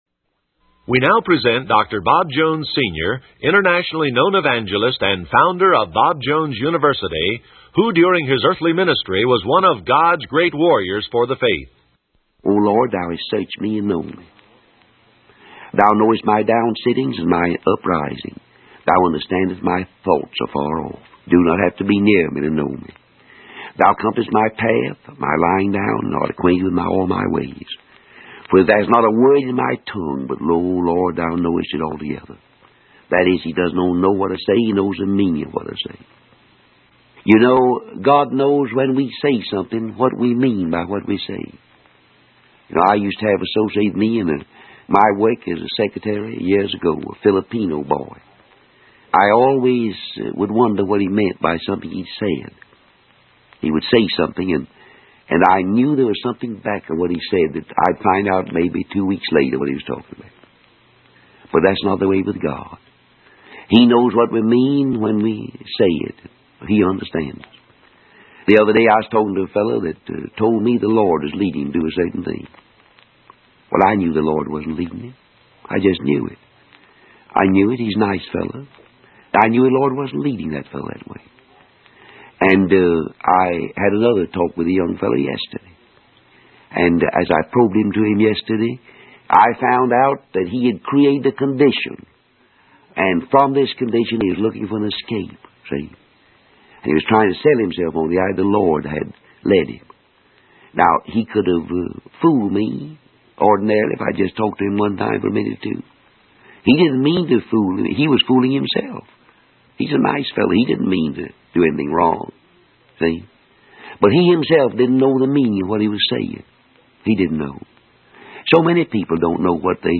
In this sermon, the preacher emphasizes the importance of completing the task at hand and not leaving it unfinished. He shares personal experiences of staying committed to evangelistic campaigns until the very end.